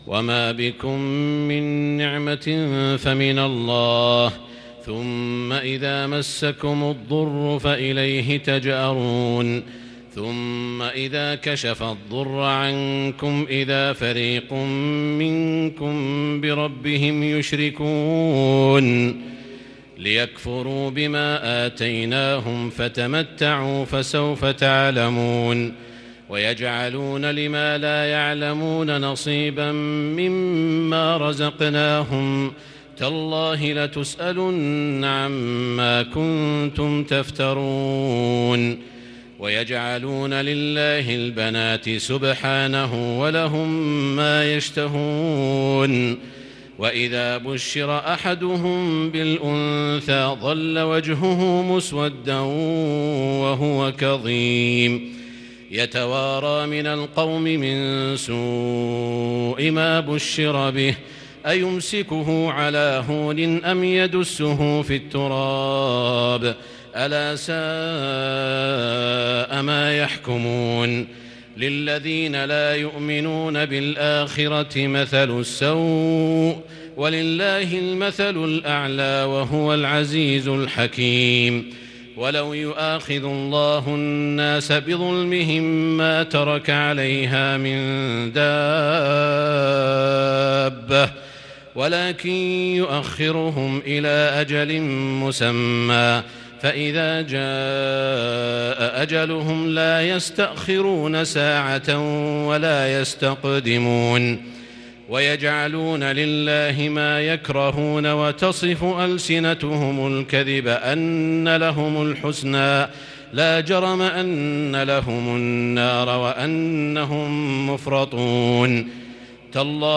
تراويح ليلة 19 رمضان 1441هـ من سورة النحل (53-119) | Tarawih prayer from Surat An-Nahl 1441H > تراويح الحرم المكي عام 1441 🕋 > التراويح - تلاوات الحرمين